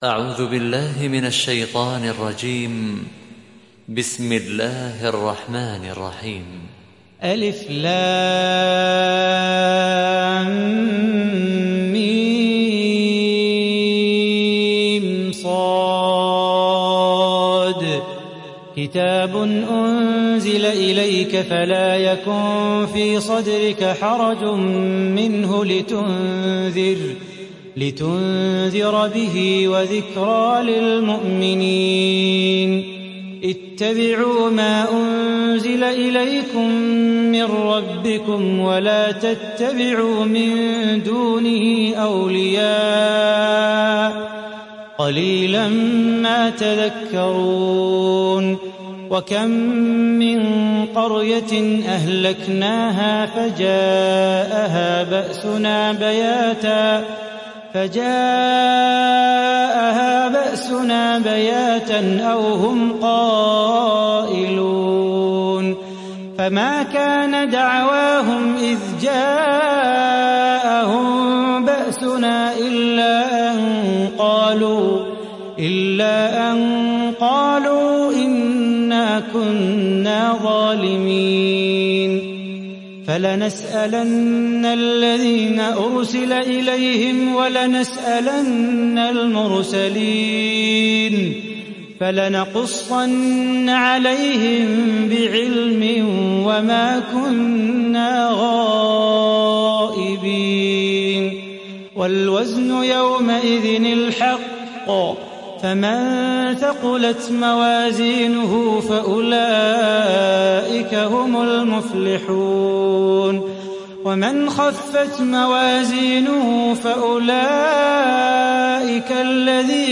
Araf Suresi İndir mp3 Salah Bukhatir Riwayat Hafs an Asim, Kurani indirin ve mp3 tam doğrudan bağlantılar dinle